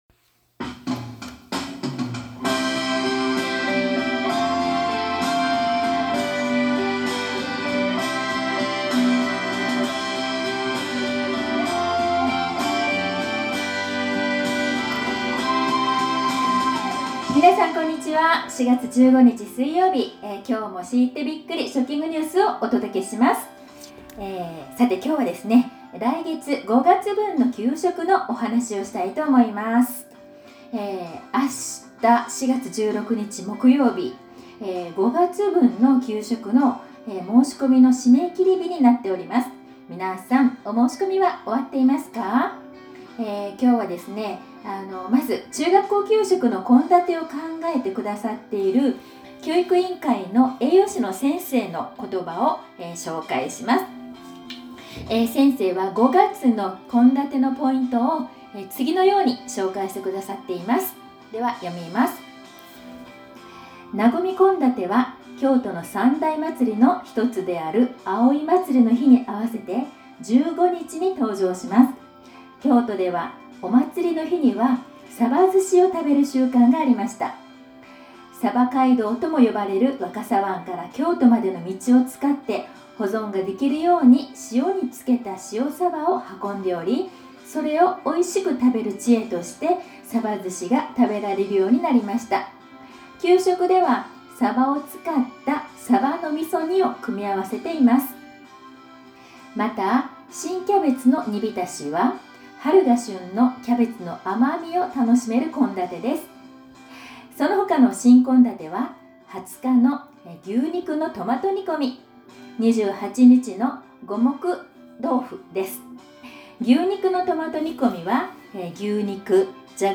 食育放送（来月の給食のこと）